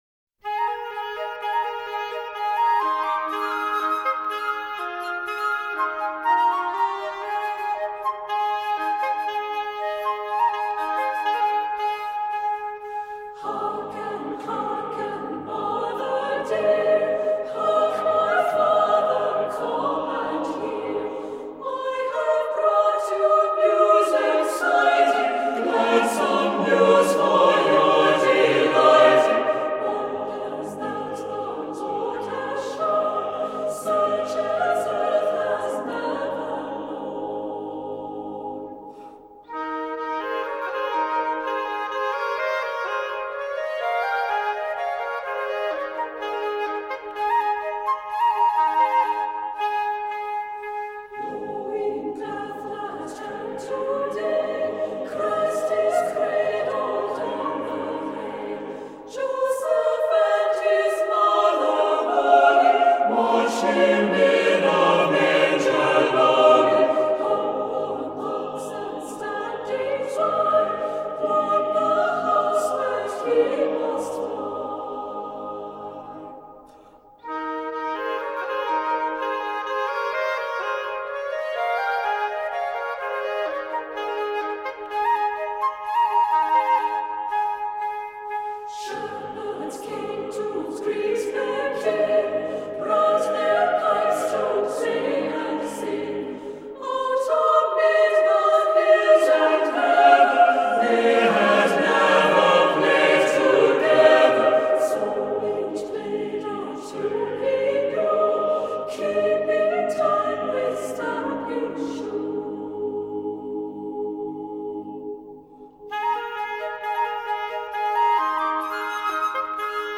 Voicing: SATBB